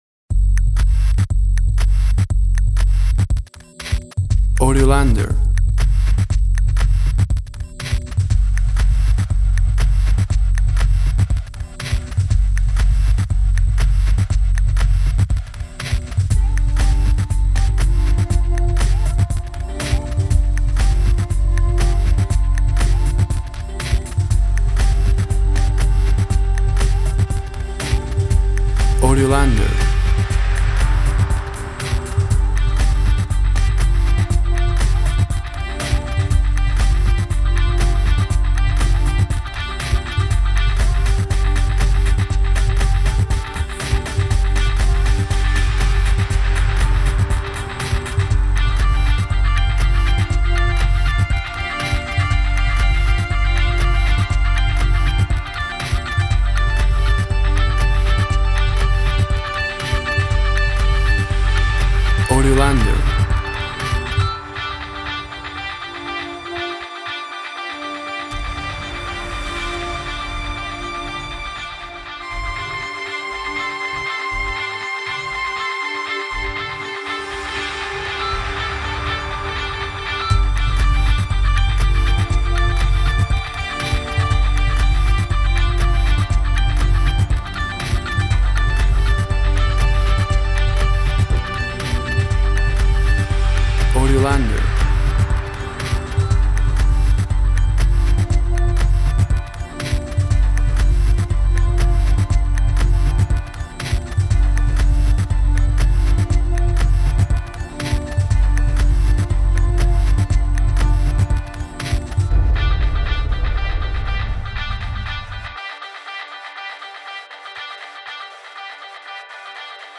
WAV Sample Rate 24-Bit Stereo, 44.1 kHz
Tempo (BPM) 120